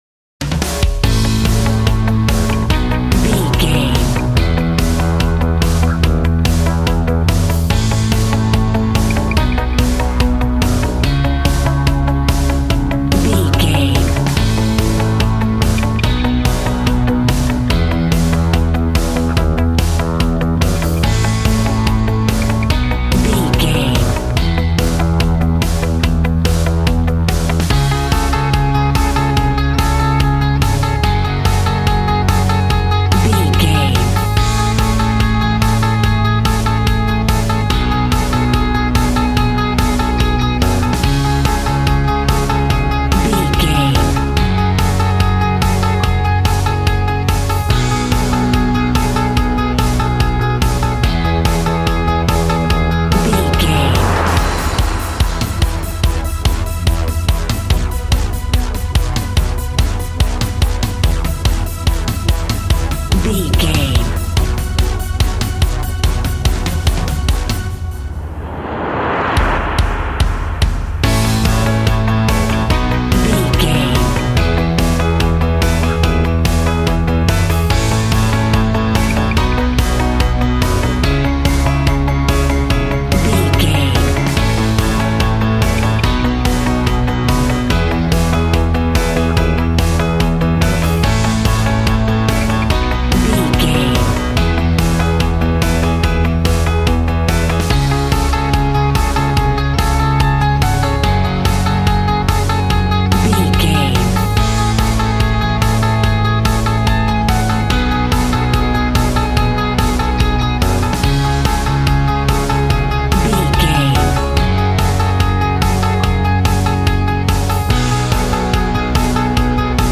Ionian/Major
groovy
fun
happy
electric guitar
bass guitar
drums
piano
organ